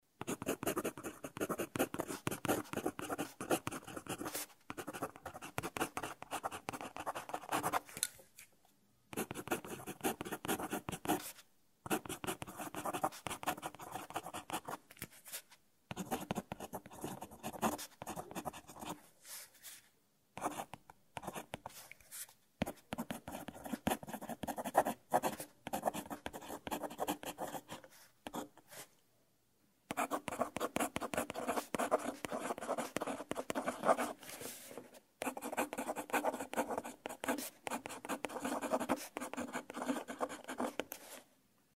В данном сборнике вы услышите звуки письма пером и чернильницы в хорошем качестве.
4. Пишут пером письмо
pisimo-perom-2.mp3